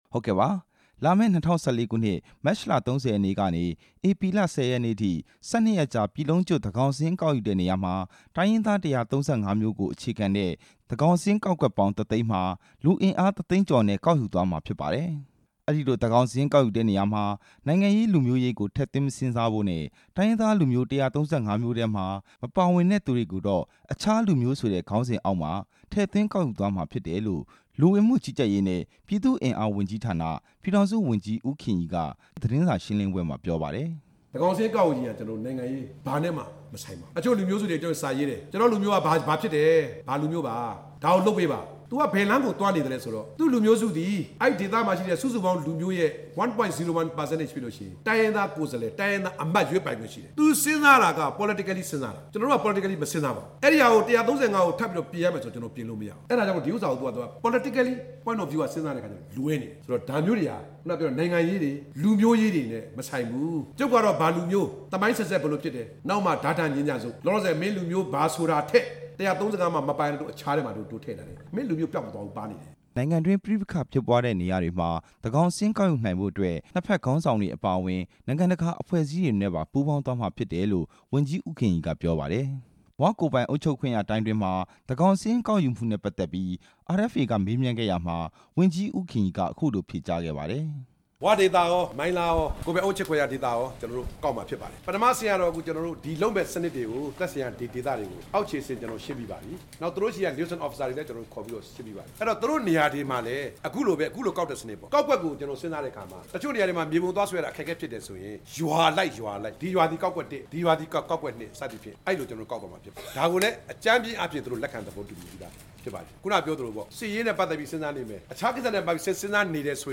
ဝန်ကြီး ဦးခင်ရီရဲ့ သတင်းစာရှင်းလင်းပွဲ